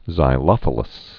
(zī-lŏfə-ləs)